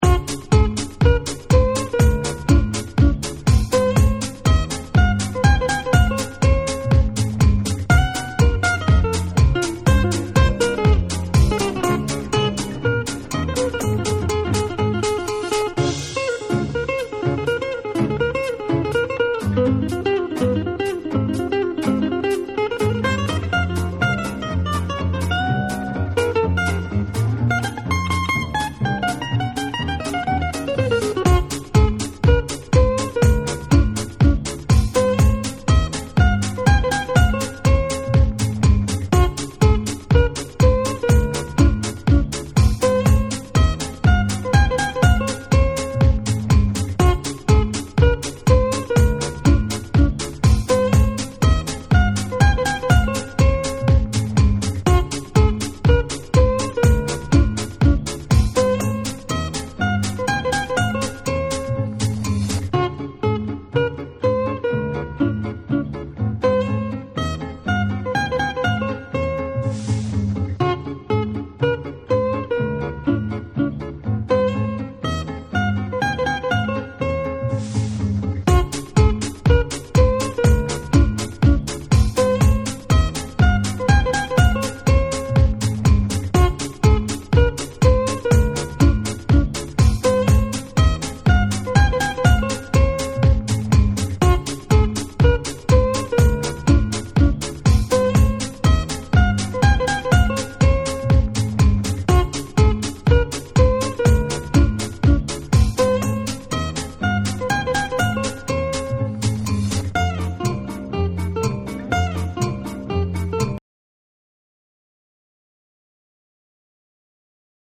TECHNO & HOUSE / BREAKBEATS